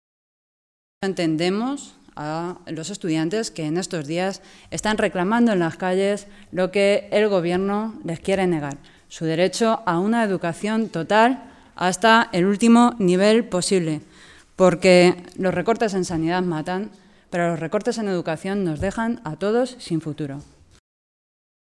Carmen Rodrigo, portavoz de Educación del Grupo Parlamentario Socialista
Cortes de audio de la rueda de prensa